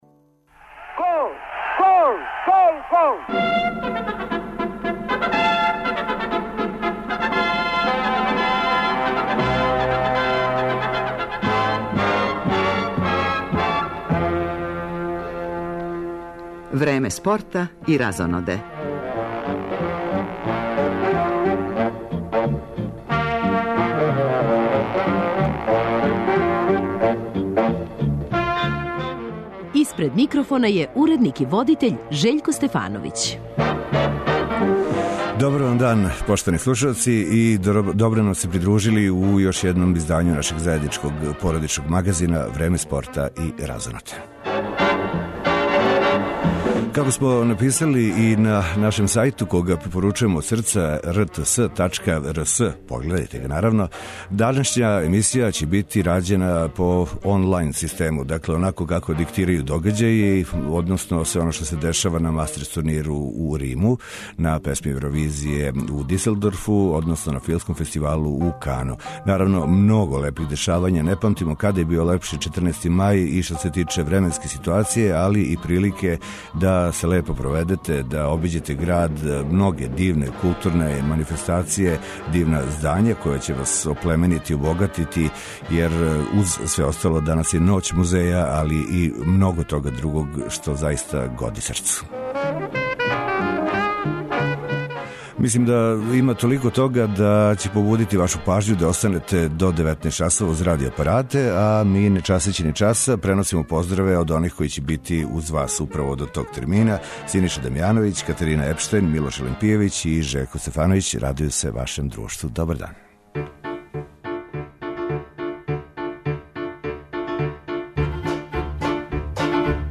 Породични магазин Време спорта и разоноде данас ће бити рађен по 'on line' систему, у зависности од мноштва догађаја у земљи и свету. Наши репортери јављају се са отварања 'Куће фудбала' у Старој Пазови, из Диселдорфа, у предвечерје финалног избора за Песму Евровизије, као и са филмског фестивала у Кану.